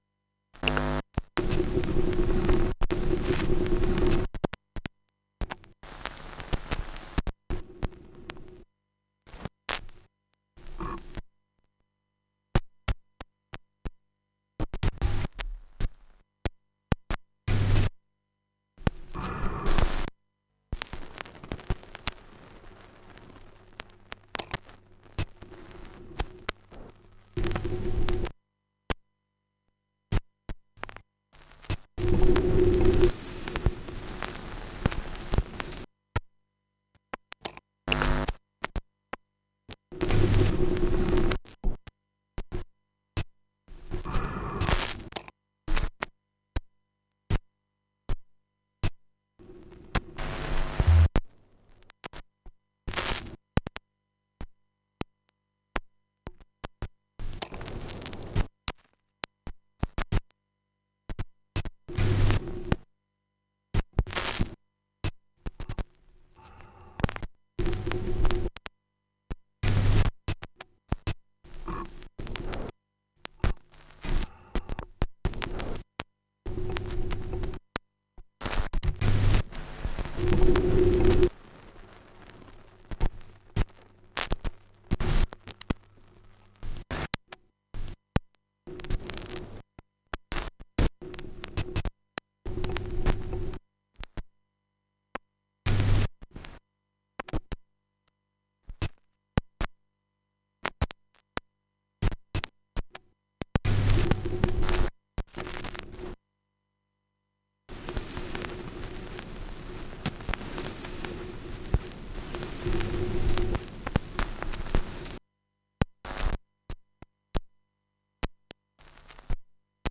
Samples, Vocoder, Sherman Filterbank
9 Flashplayer spielen die gleichen Samples wie beim Konzert.